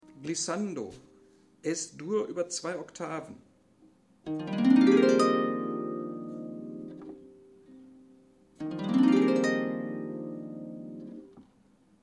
Das Glissando ist ein Gleiten über die Saiten; ein einzelner Finger "surft" über die Saiten und läßt sie schnell nacheinander ohne Ausnahme erklingen.
Hörbeispiel Glissando